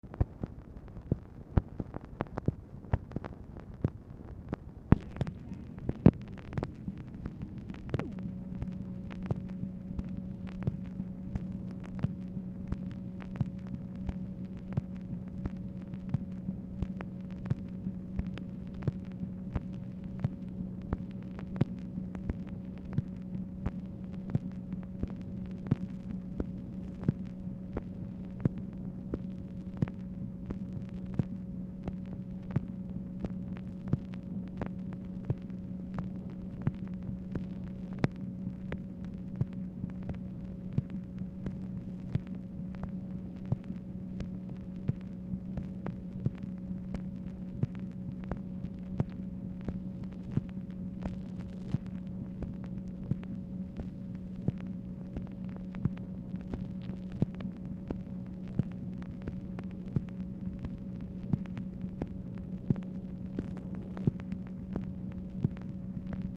Telephone conversation # 8557, sound recording, MACHINE NOISE, 8/18/1965, time unknown | Discover LBJ
Format Dictation belt